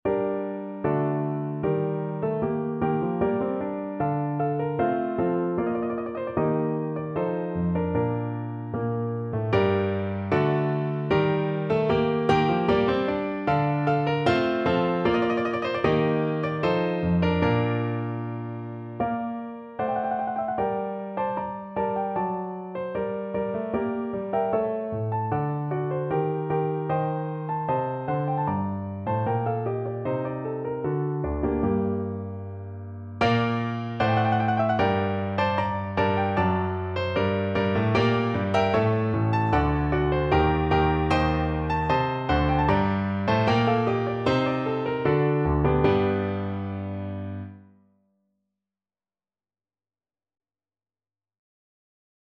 No parts available for this pieces as it is for solo piano.
2/2 (View more 2/2 Music)
Piano  (View more Intermediate Piano Music)
Classical (View more Classical Piano Music)